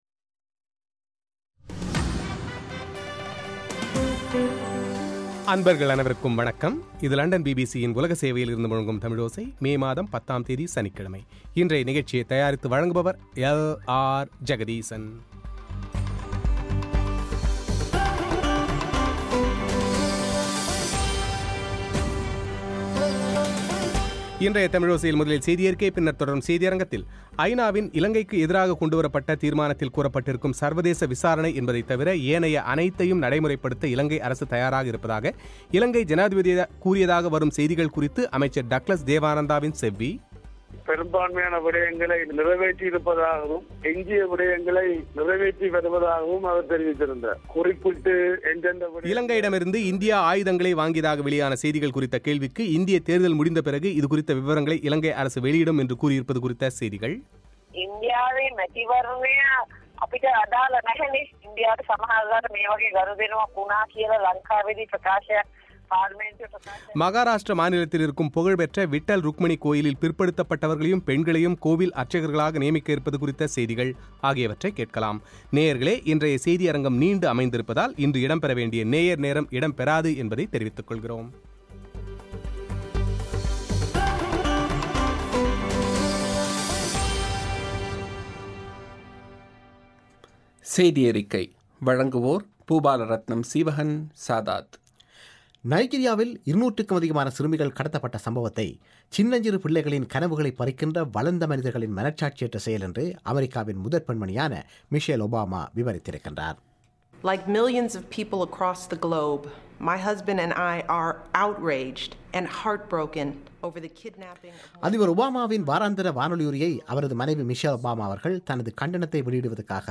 ஐநாவின் ஜெனிவா மனித உரிமைகள் ஆணைக்குழுவில் இலங்கைக்கு எதிராக கொண்டுவரப்பட்ட தீர்மானத்தில் கூறப்பட்ட சர்வதேச விசாரணை என்பதைத்தை தவிர ஏனைய அனைத்தையும் நடைமுறைப்படுத்த இலங்கை தயாராக இருப்பதாக இலங்கை ஜனாதிபதி கூறியதாக சரும் செய்திகள் குறித்து அமைச்சர் டக்ளஸ் தேவானந்தாவின் செவ்வி;